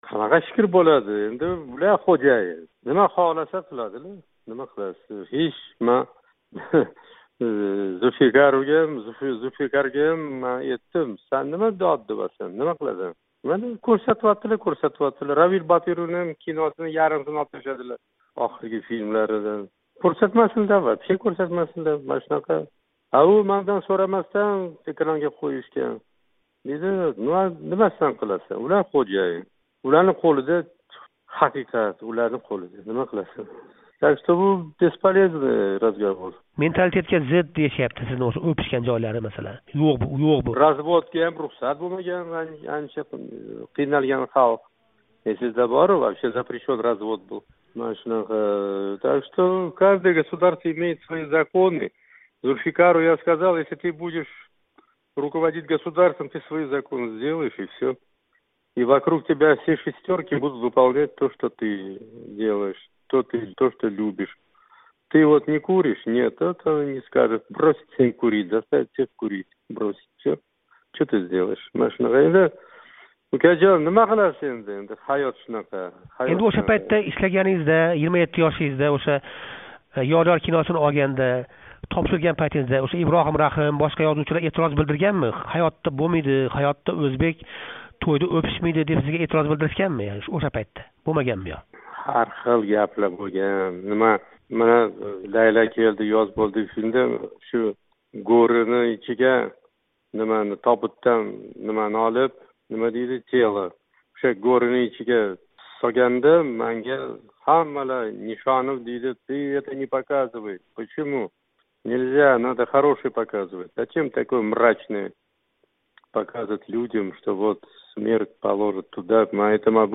Али Ҳамроев билан суҳбат